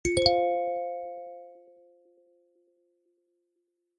Chord.mp3